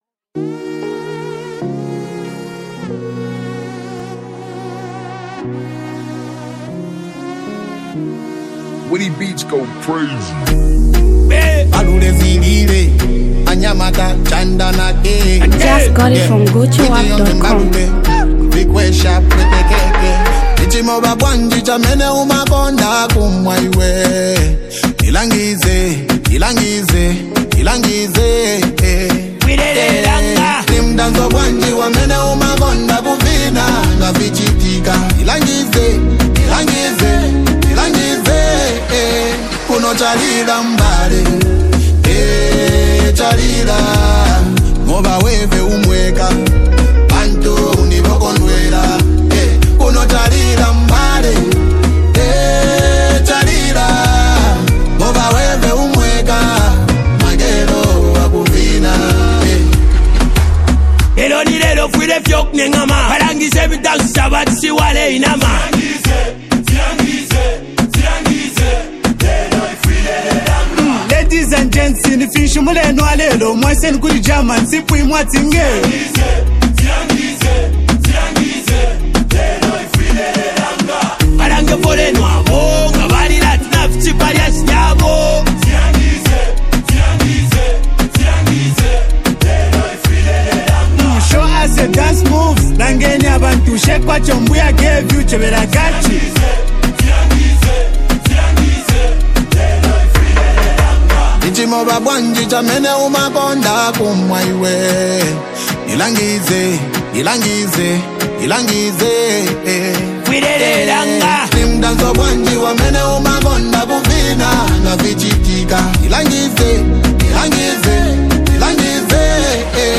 hardcore rap style